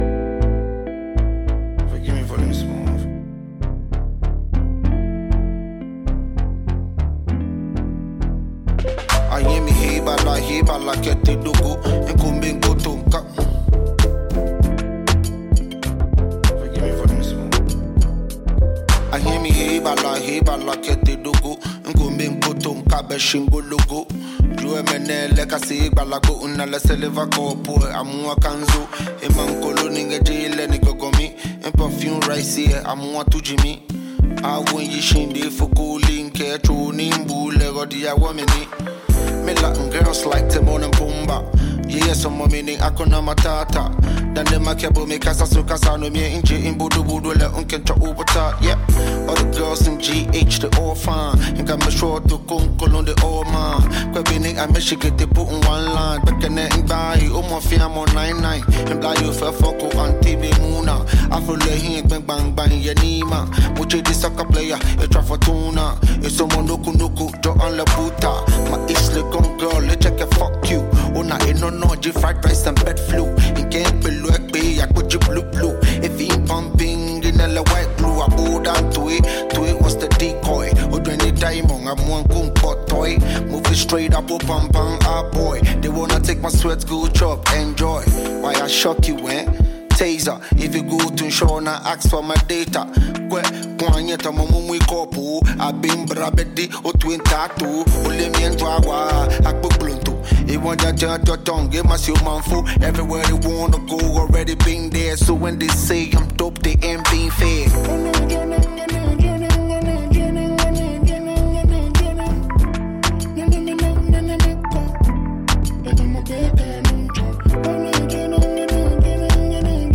Ghana Music
hip hop